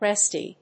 発音